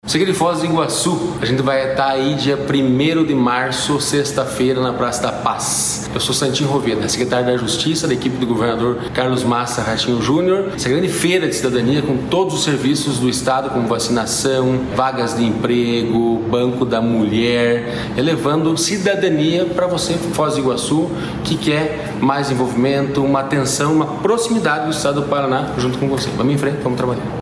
Sonora do secretário da Justiça e Cidadania, Santin Roveda, sobre a realização do Paraná Rosa em Ação em Foz do Iguaçu em março